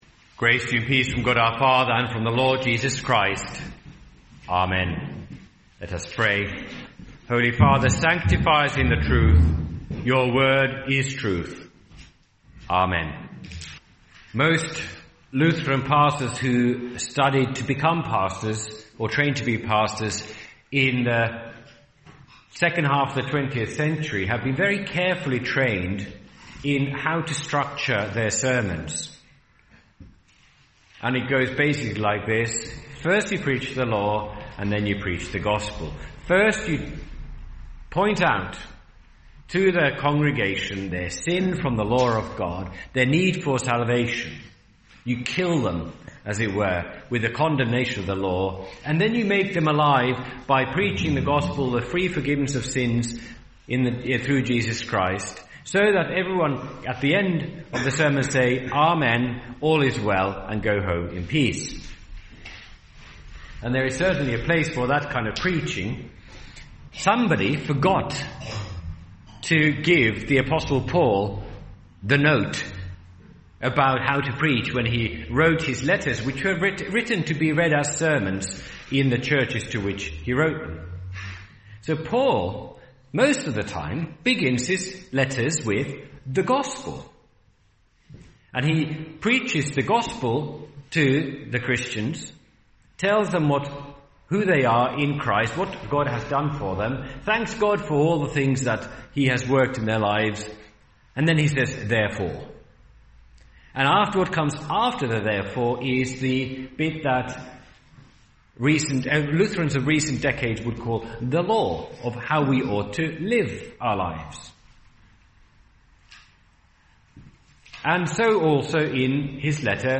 by admin | Oct 10, 2021 | Sermons, Trinity, Trinity 19